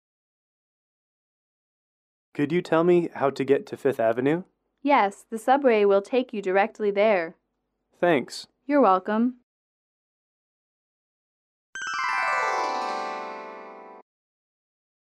英语口语情景短对话20-1：问路(MP3)